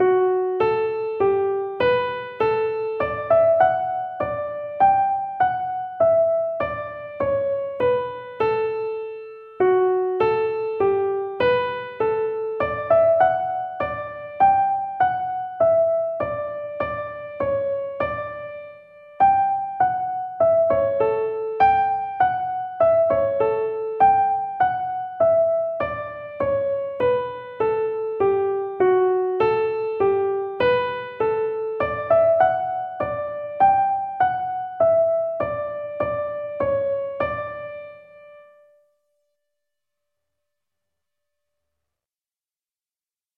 Each tune is available as a PDF (sheet music) and MP3 (audio recording played slowly for learning).